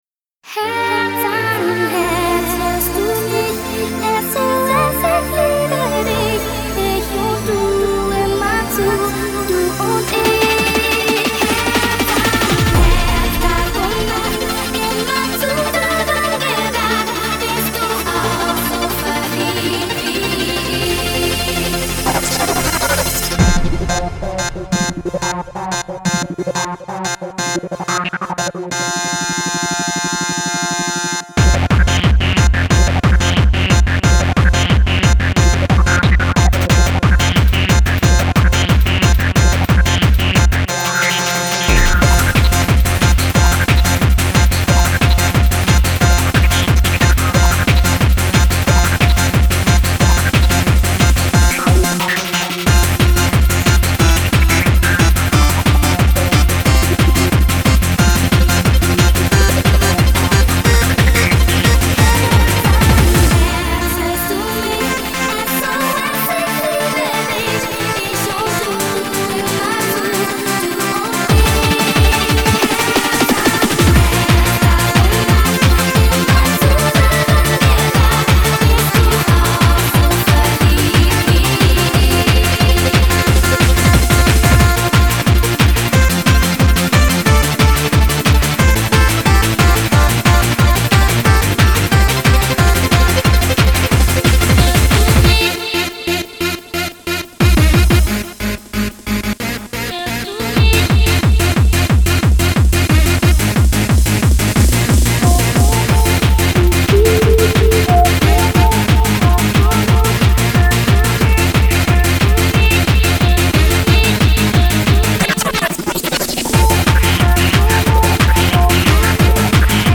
Genre: Hard Trance, Techno, Rave, Hardcore, Dance.